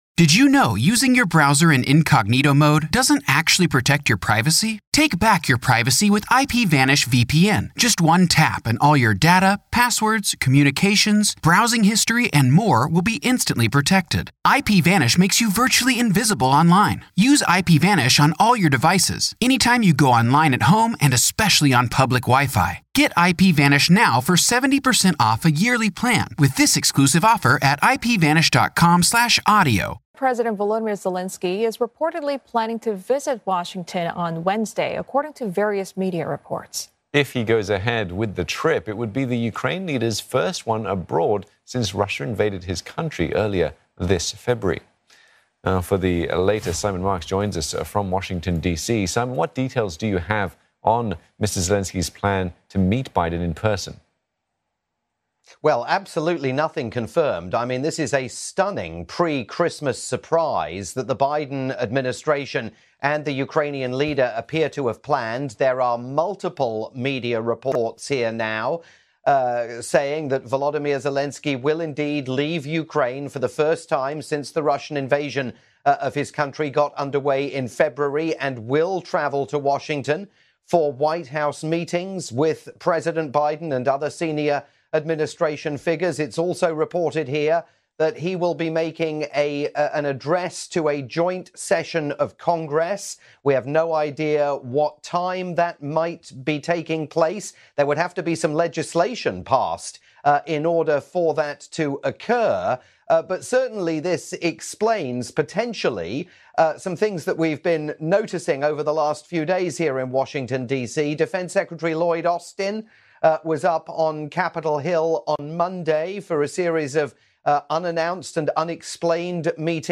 live breaking news update